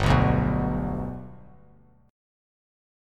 G Chord
Listen to G strummed